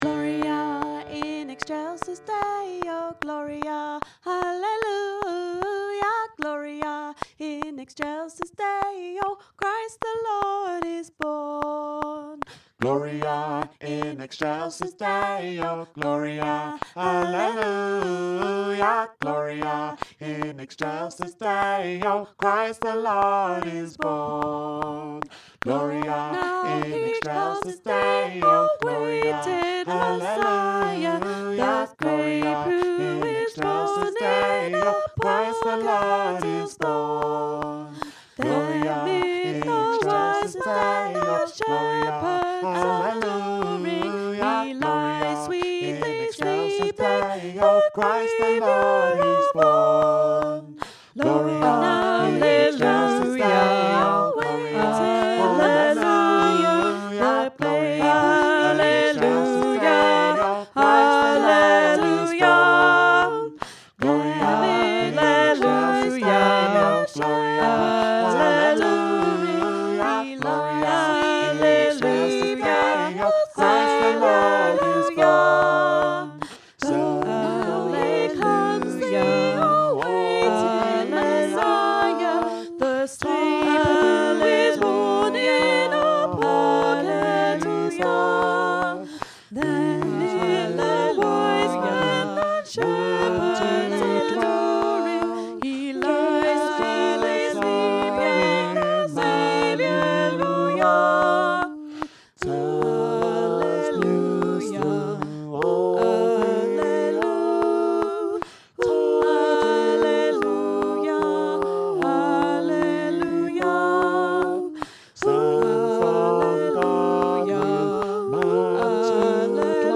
JazzGloriaArrangedAllParts.mp3